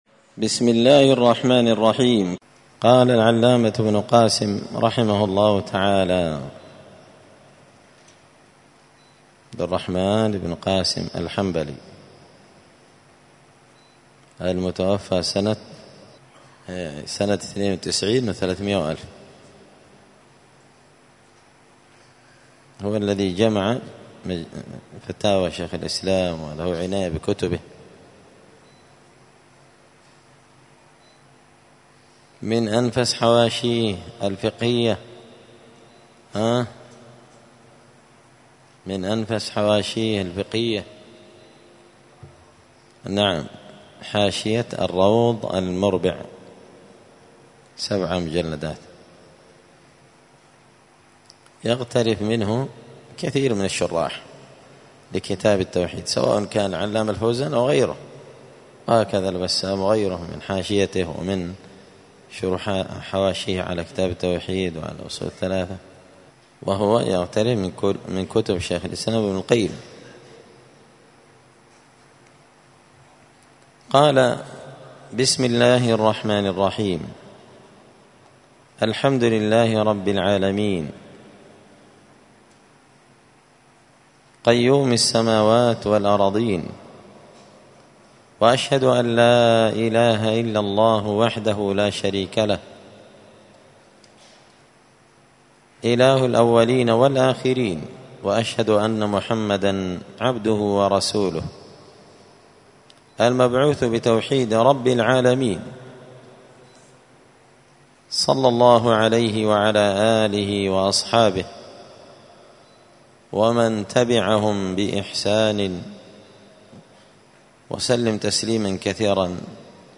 دار الحديث السلفية بمسجد الفرقان بقشن المهرة اليمن
2الدرس-الثاني-من-كتاب-حاشية-كتاب-التوحيد-لابن-قاسم-الحنبلي.mp3